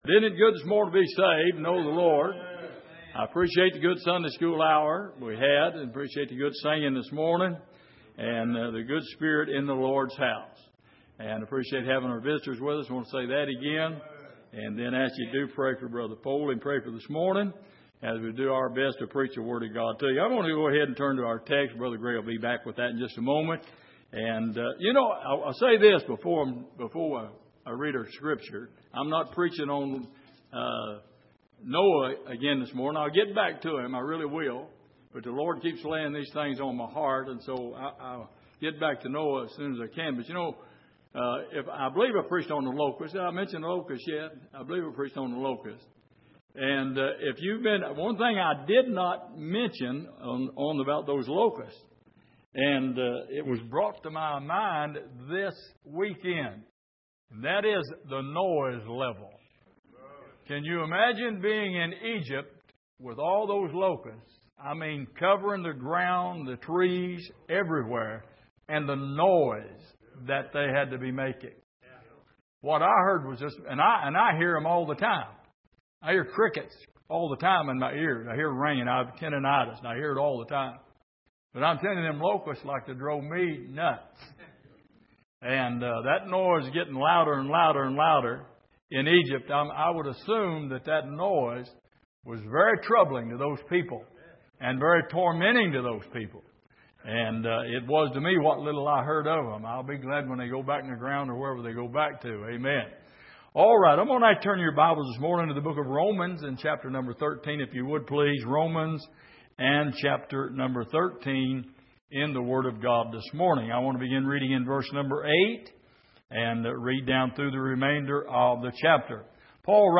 Passage: Romans 13:11 Service: Sunday Morning